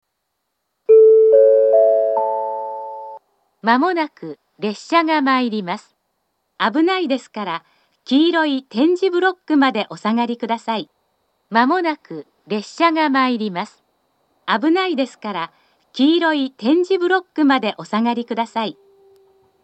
スピーカーからは接近放送が、電子電鈴からはベルが流れます。
２０２５年４月には再度放送装置が更新されているのが確認され、「黄色い点字ブロックまで」と言う放送になっています。
１番線接近放送